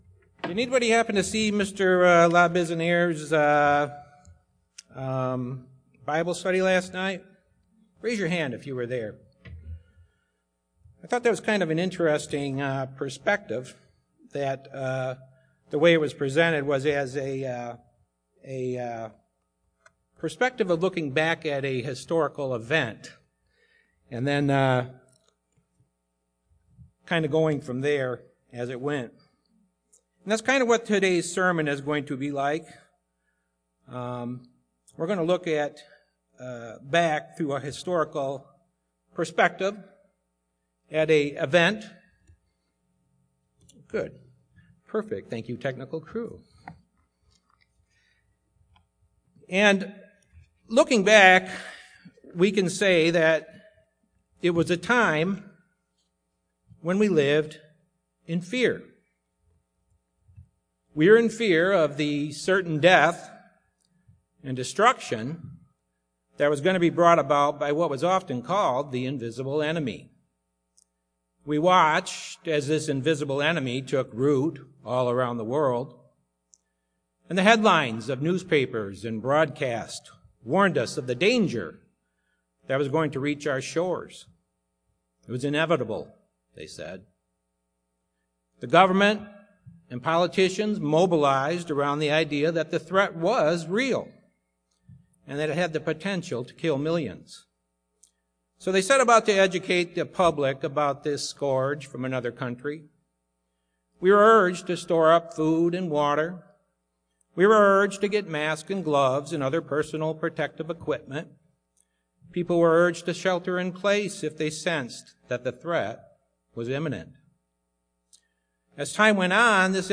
This sermon was given at the Pewaukee, Wisconsin 2020 Feast site.